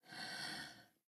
assets / minecraft / sounds / mob / turtle / idle2.ogg